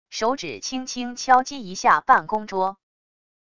手指轻轻敲击一下办公桌wav音频